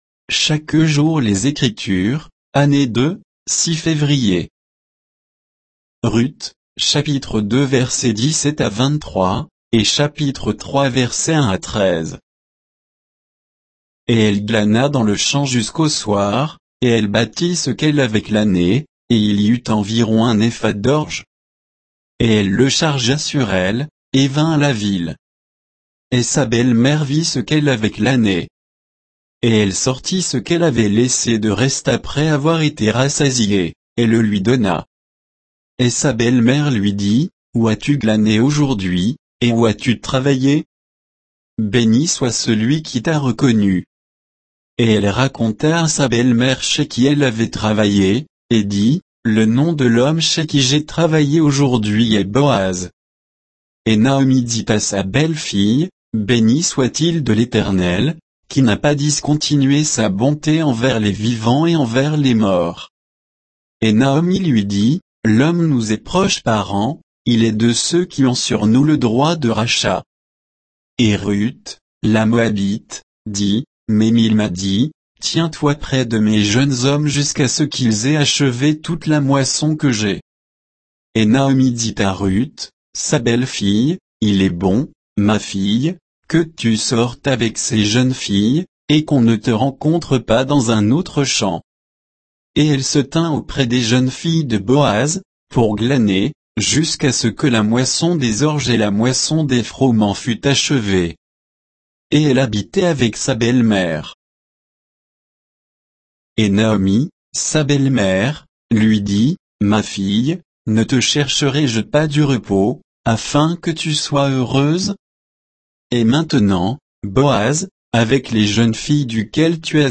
Méditation quoditienne de Chaque jour les Écritures sur Ruth 2, 17 à 3, 13